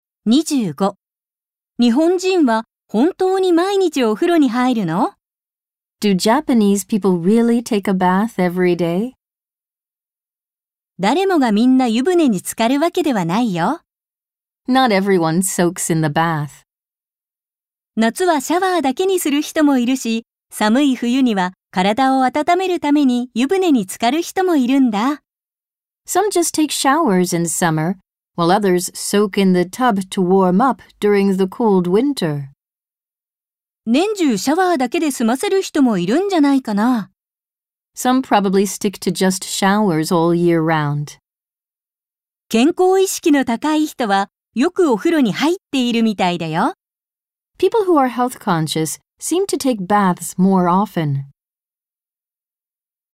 ・ナレーター：アメリカ英語のネイティブ（女性）と日本語ナレーター（女性）
・スピード：ナチュラル